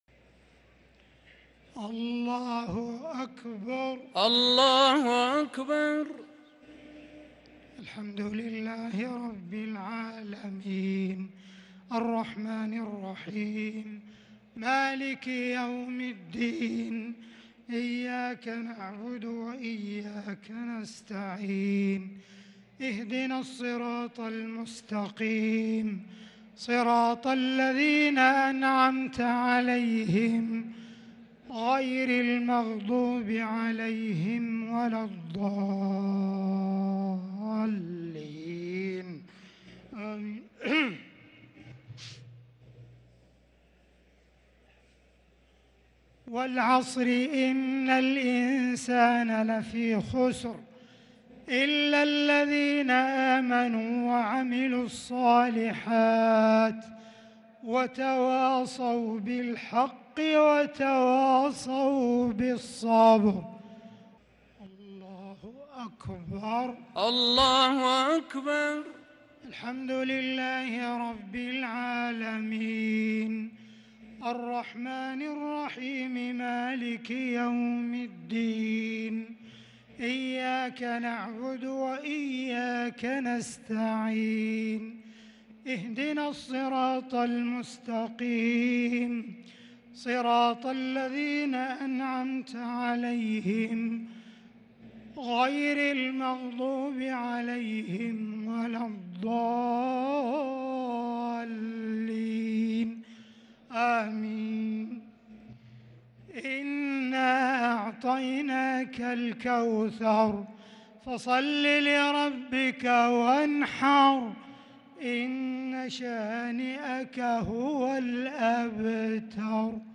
صلاة الشفع والوتر ليلة 1 رمضان 1443هـ Witr 1st night Ramadan 1443H > تراويح الحرم المكي عام 1443 🕋 > التراويح - تلاوات الحرمين